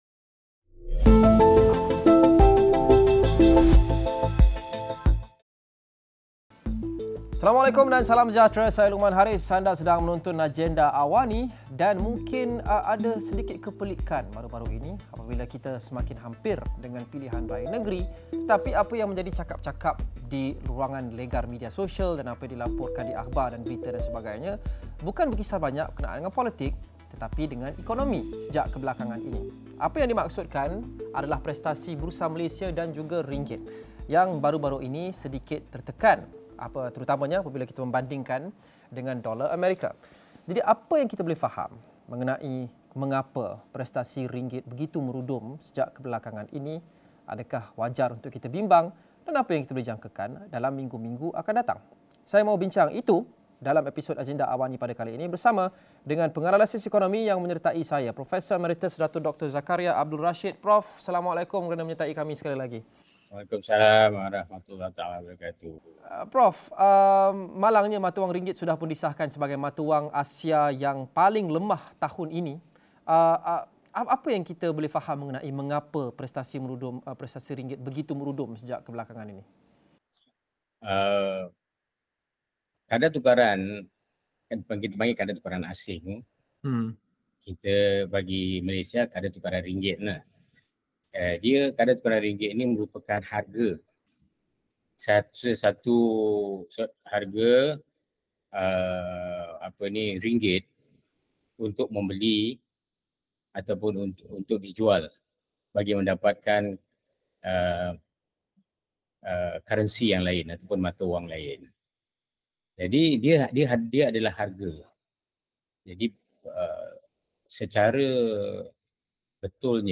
Prestasi mata wang ringgit yang semakin lemah, sejauh mana ia mempengaruhi pertumbuhan ekonomi negara dan kesannya terhadap rakyat? Diskusi 8.30 malam